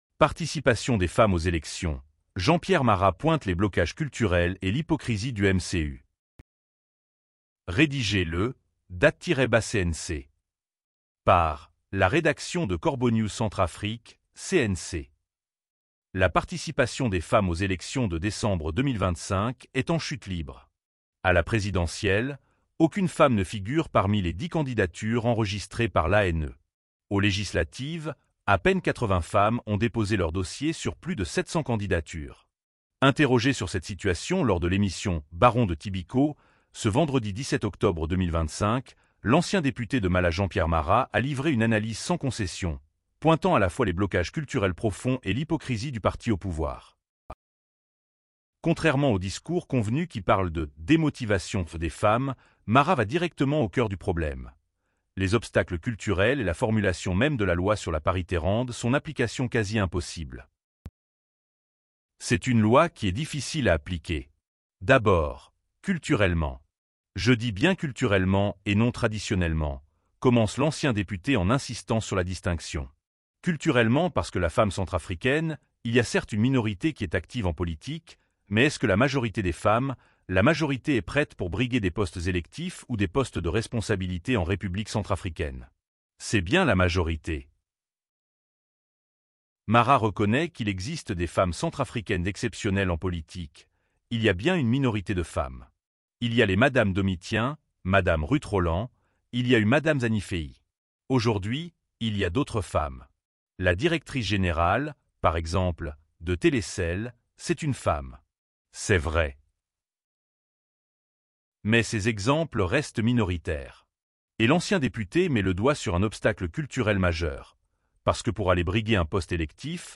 Interrogé sur cette situation lors de l’émission “Baron de Tibico” ce vendredi 17 octobre 2025, l’ancien député de Mala Jean-Pierre Mara a livré une analyse sans concession, pointant à la fois les blocages culturels profonds et l’hypocrisie du parti au pouvoir.